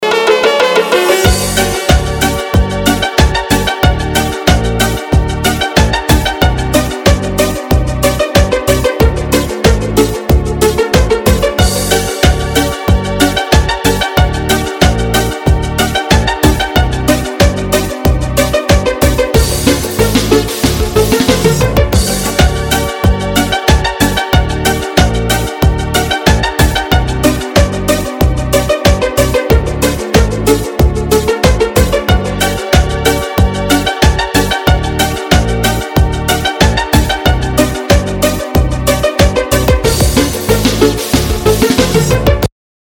Веселые рингтоны
Ретро , поп